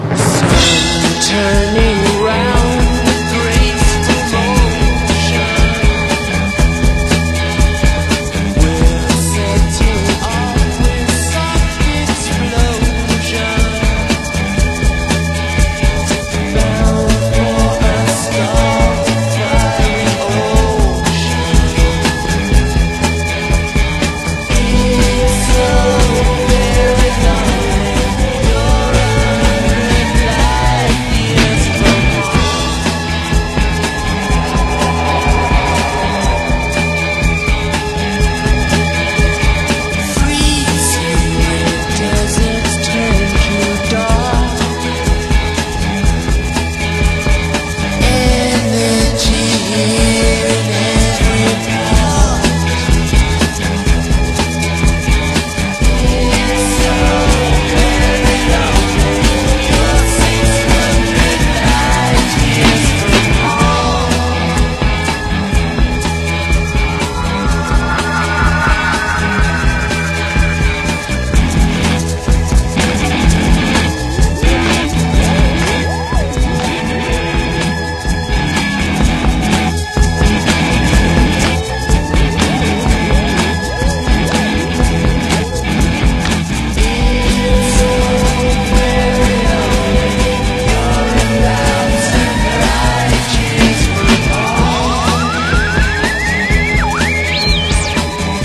オーストラリア現地のローカル・ラウンジ・ミュージック！